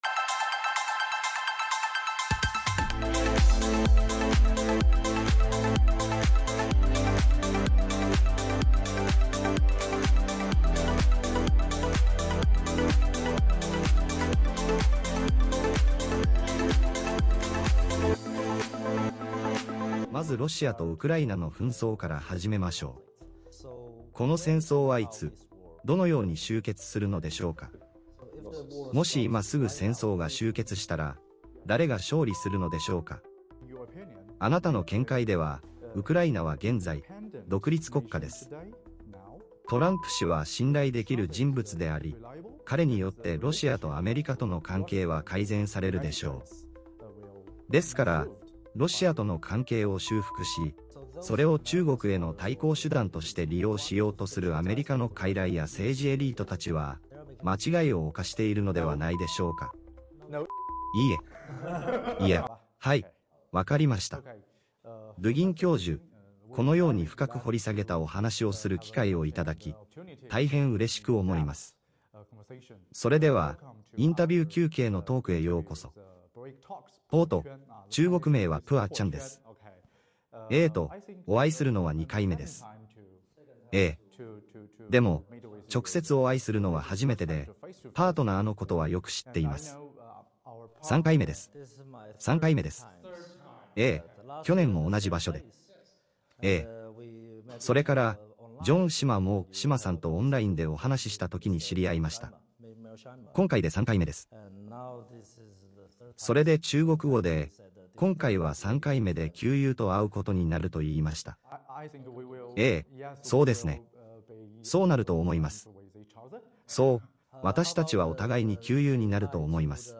RU アレクサンダー・ドゥーギン独占インタビュー 「ロシア・ウクライナ戦争では誰も勝てない」 (概要欄に要約あります) 2025年５月23日